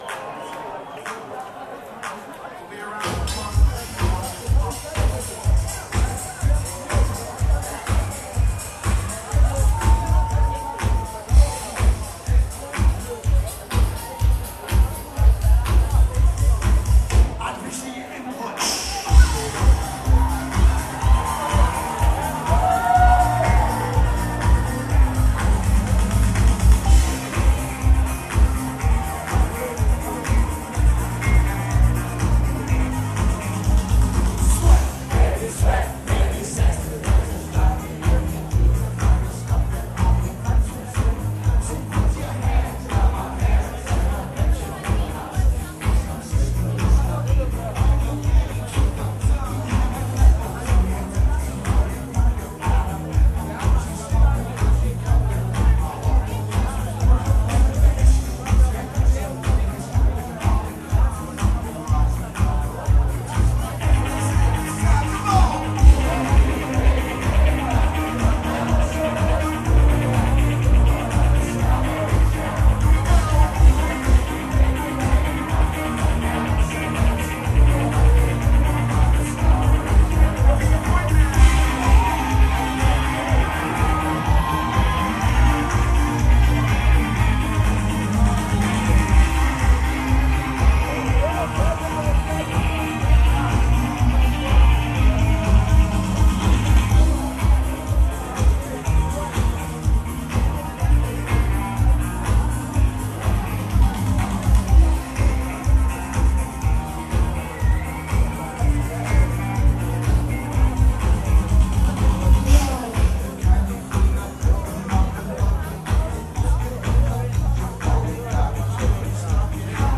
Recorder: Sharp IM-DR420H (mono mode)
Microphone: Sony ECM-T6 (mono)